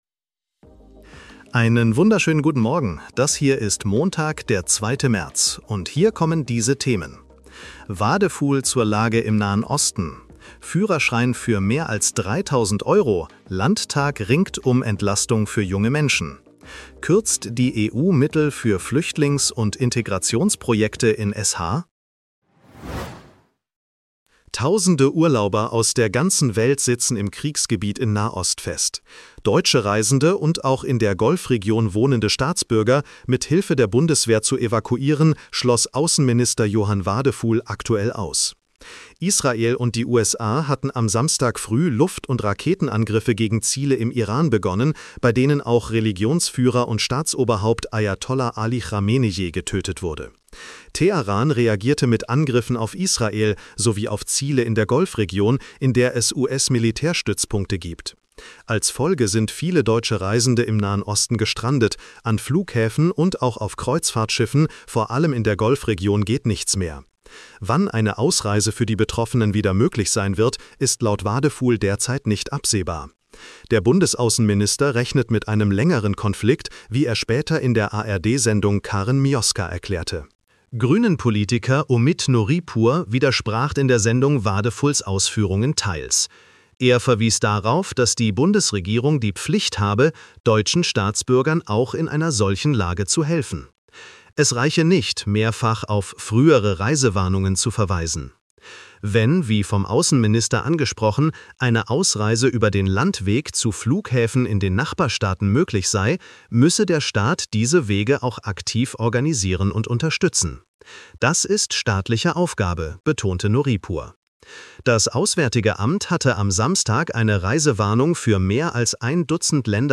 Nachrichten-Botcast bekommst Du ab 7:30 Uhr die wichtigsten Infos